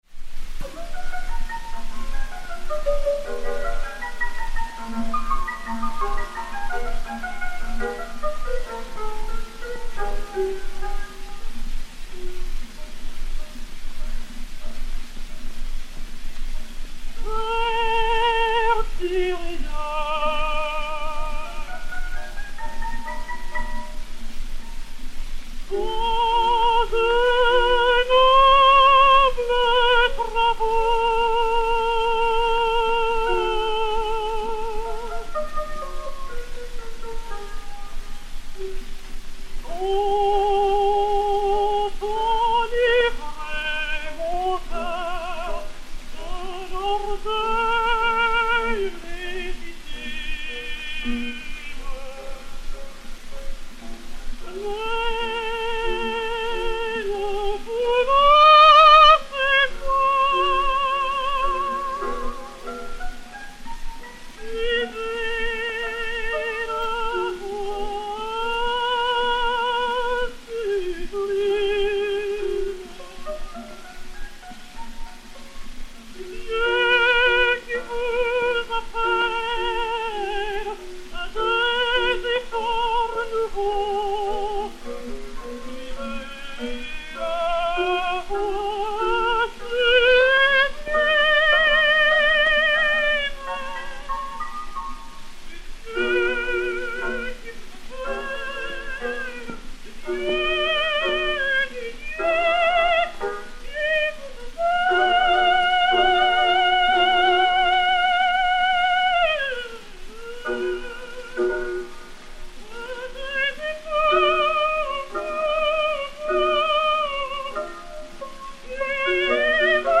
Marie Delna (Didon, créatrice à l'Opéra-Comique) et Piano
Pathé saphir 90 tours n° 3513, réédité sur 80 tours n° 213, enr. en 1903/1904